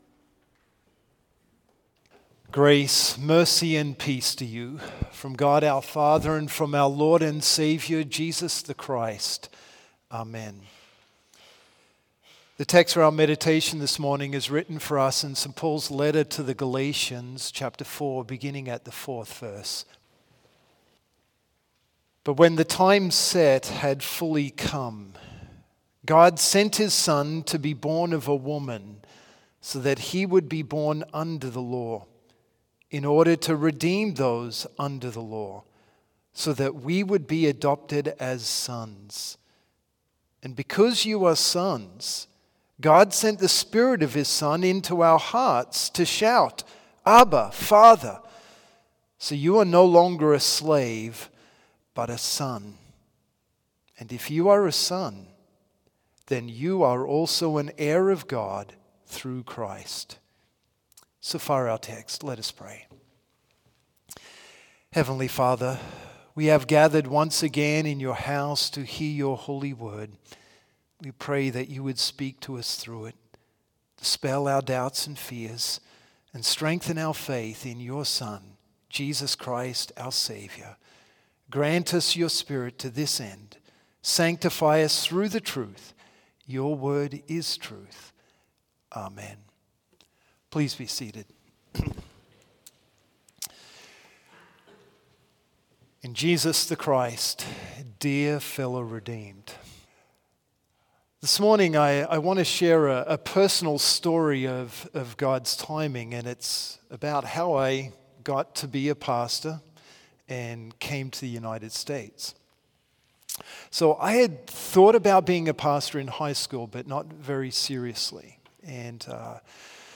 Complete service audio for Chapel - Thursday, December 14, 2023